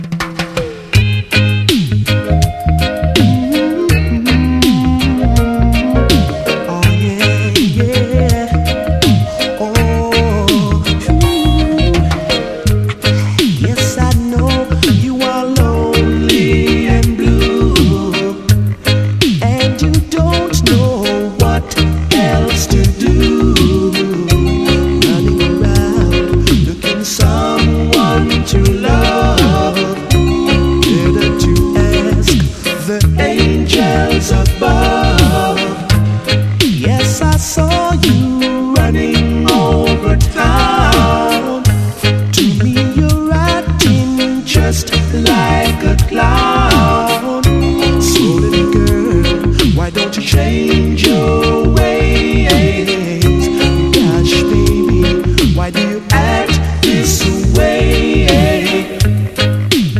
WORLD / REGGAE / RAGGA / DANCEHALL / DANCEHALL REGGAE (UK)
マシンガンのような軽快なリズムマシン使いが痛快な87年ラガ/ダンスホール！